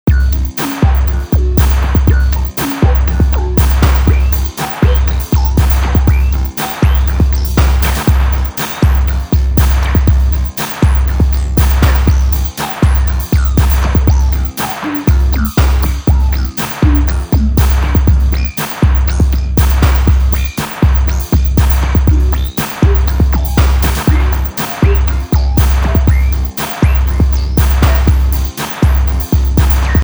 まあ、オシレーターを6基も使ってるわりには、普通のパッチングというか、普通のシンセサイザー的な信号の流れですね(^^;; これをMoog Modular V2のアナログ・シーケンサー・モジュールでVCOとVCFをコントロールしながら、フィルター・バンクとディレイ・モジュールを通過させて、少しスペシーな雰囲気にしつつ、、、
せっかくだからと、「Logic Pro」を立ち上げてAudioに落として、、、 で、 せっかくだからと、Logic Proの「Ultrabeat」で音を作りつつリズムトラックを、何となくAudioに落としてみました。